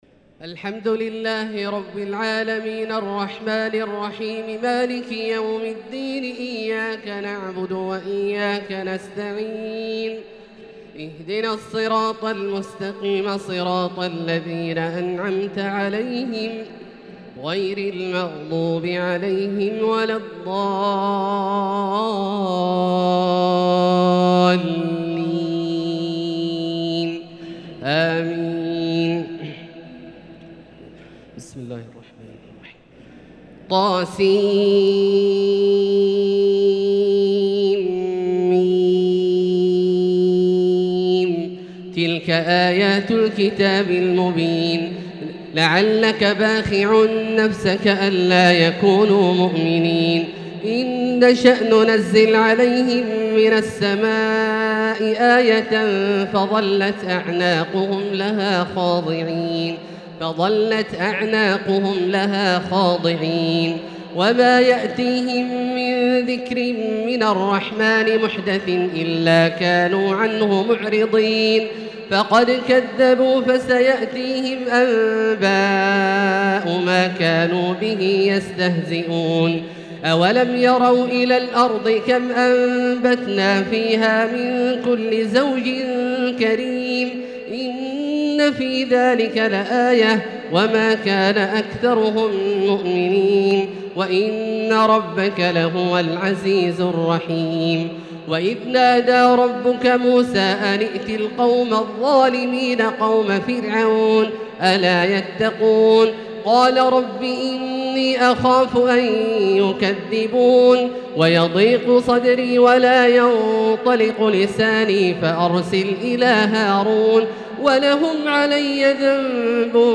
تراويح ليلة 23 رمضان 1443 هـ من سورة الشعراء (1-207) | Taraweeh prayer 23 St night Ramadan 1443H from surah Ash-Shuara > تراويح الحرم المكي عام 1443 🕋 > التراويح - تلاوات الحرمين